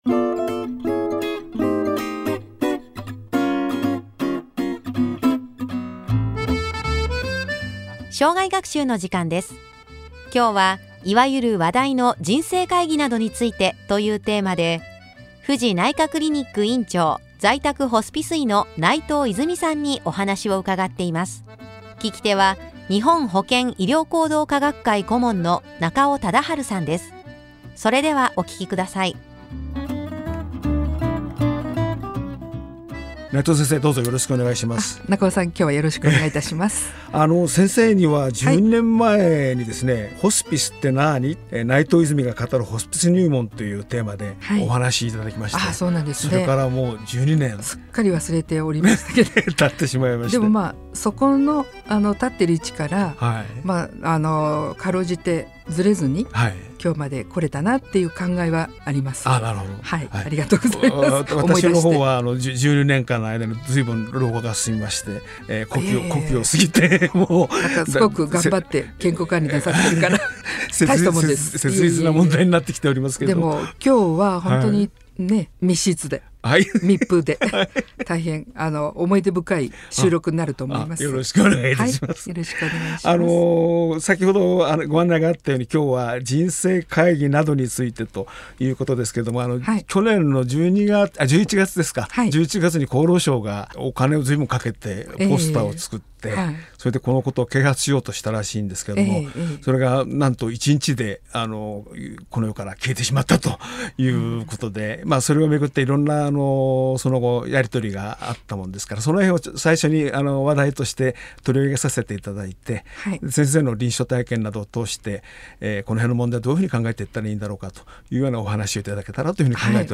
大学の構内にあるラジオ局からご一緒に喋らせていただいた。